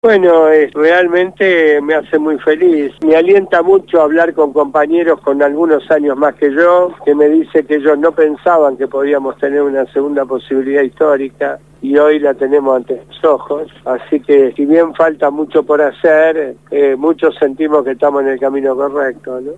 El dirigente social Luis D´Elía fue entrevistado en «Voces Portuarias» (Martes, de 18:00 a 19:00hs, programa del Sindicato Único de Portuarios de Argentina).